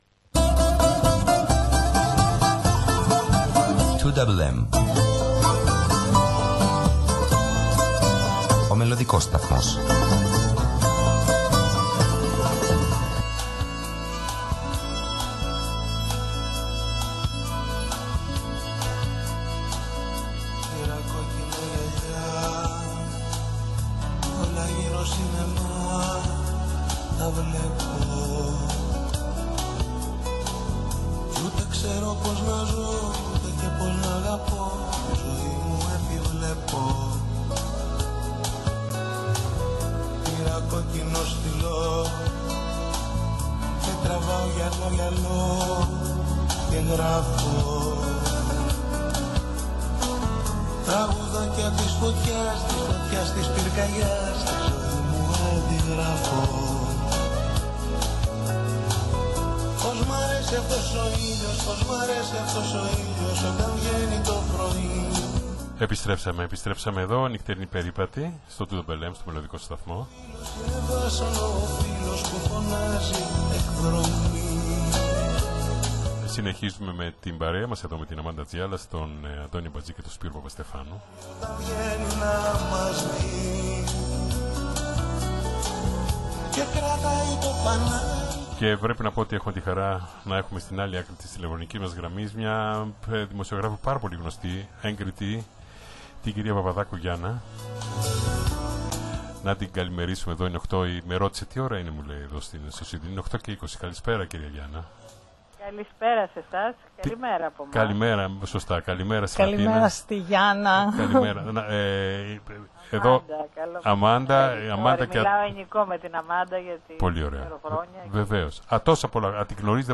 ζωντανή συνέντευξή